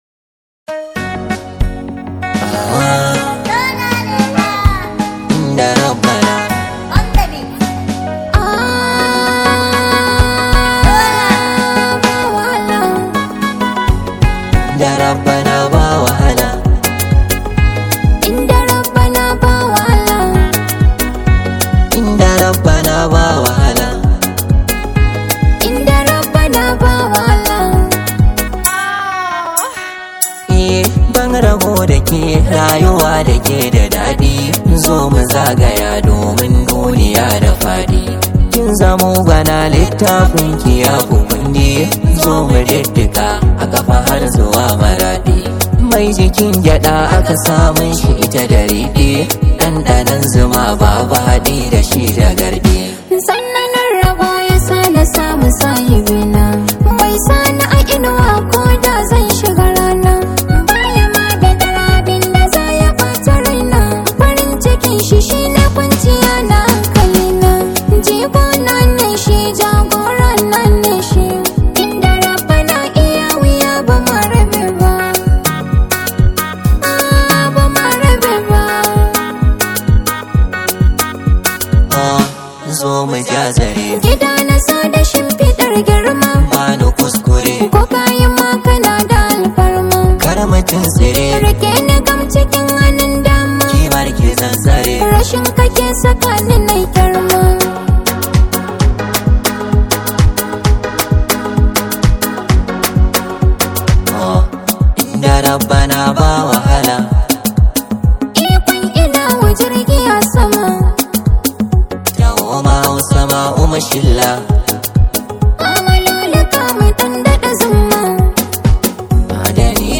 Kyakkyawan zubi da lafazi mai sanyaya zuciya.